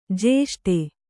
♪ jēṣṭe